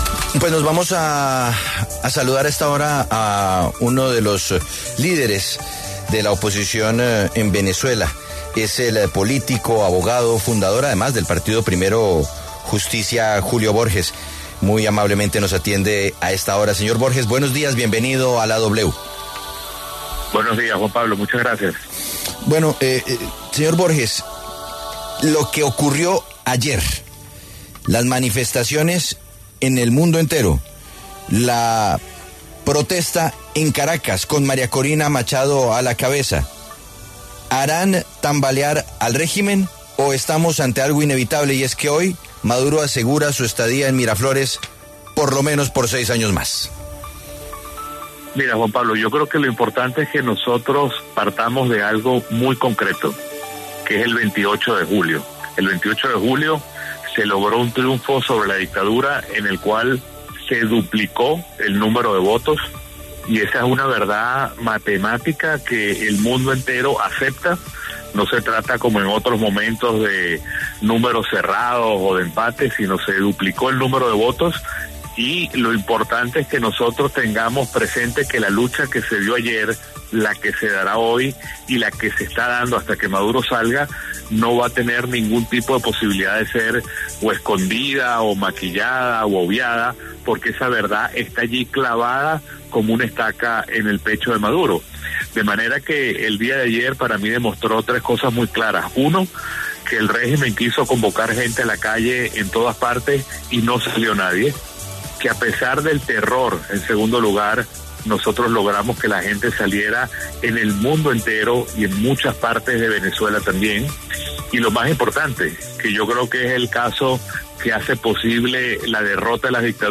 El líder opositor venezolano Julio Borges pasó por los micrófonos de La W, con Julio Sánchez Cristo, para hablar sobre las manifestaciones que se dieron en Venezuela en contra de la posesión de Nicolás Maduro, acto que se llevará a cabo este viernes, 10 de enero.